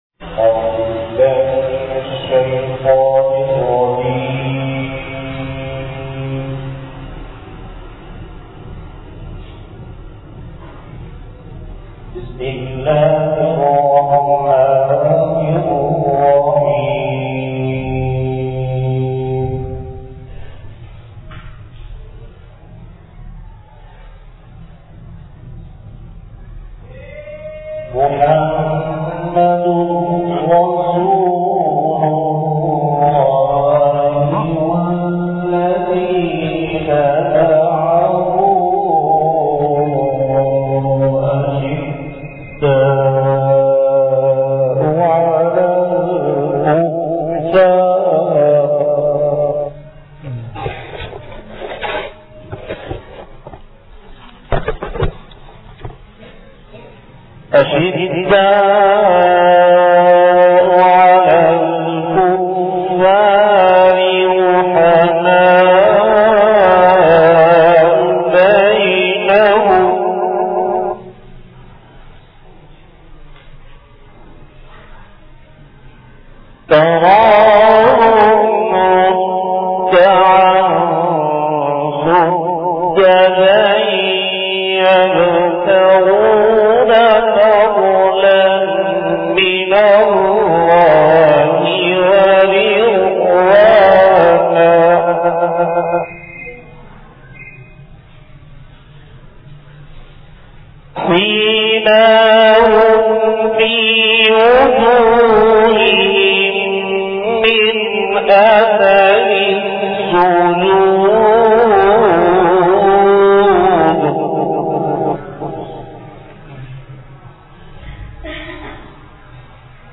بیان بعد نمازظہر مدرسہ ابو بکر مرکز امداد و اشرف نزد معمار ہاؤسنگ کراچی